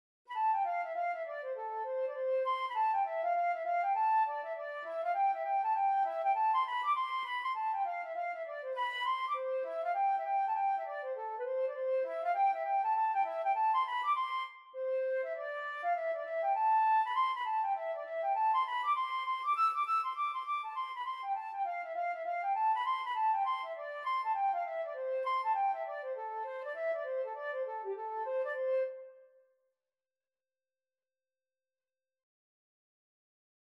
2/4 (View more 2/4 Music)
G5-E7
C major (Sounding Pitch) (View more C major Music for Flute )
Flute  (View more Intermediate Flute Music)
Traditional (View more Traditional Flute Music)